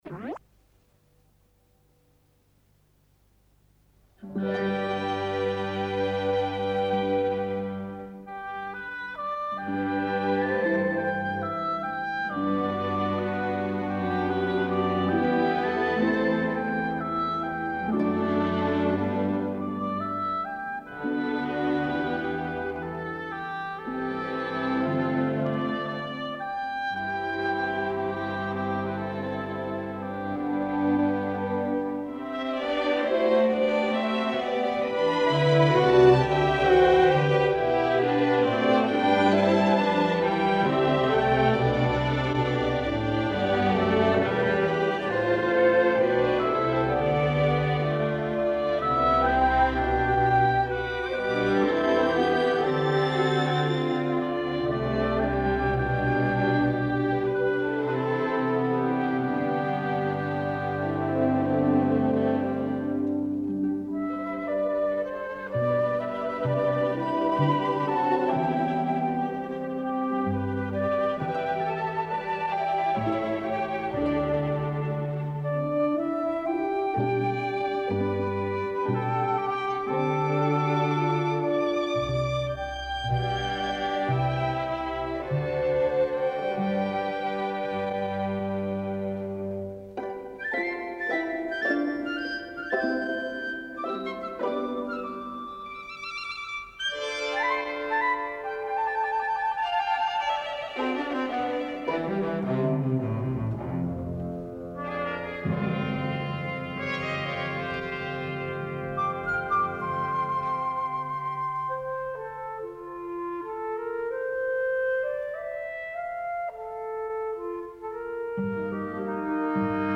Solo oboe, to a luscious string and harp accompaniment, opens the account with a captivatingly atmospheric folksong melody – unidentified, as such, but presumably a Co. Antrim tune – or maybe even an original Friel melody. The full complement of the strings takes over but we hear again the wistful oboe sliding in at the end of the measure which rounds off with rich horn and harp sounds. An extremely pleasing tableau follows on from this with solo flute to plucked bass and tremolando strings, but then we hear a clarinet to bowed violin strings playing the same theme as the oboe drifts away.